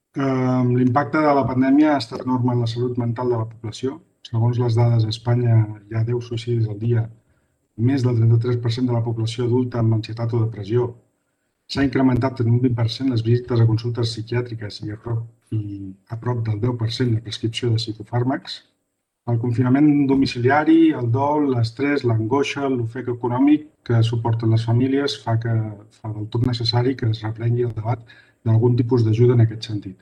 Isaac Martínez, portaveu En Comú Podem